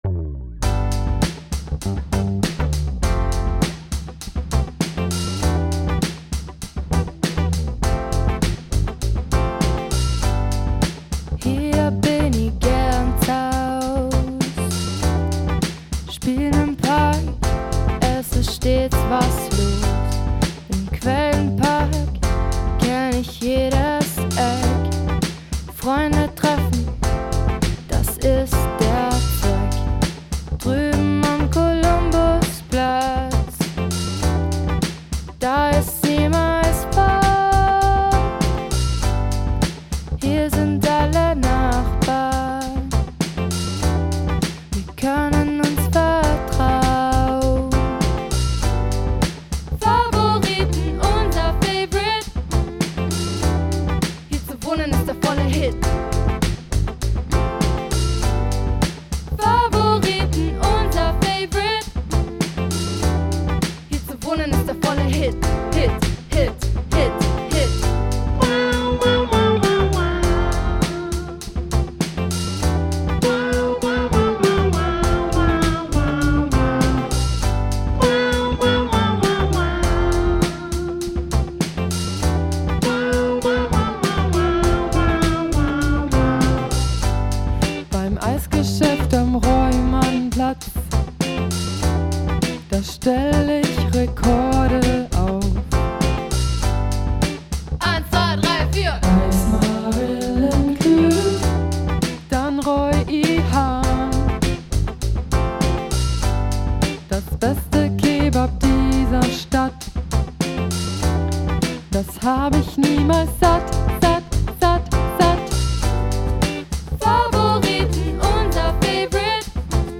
voc, git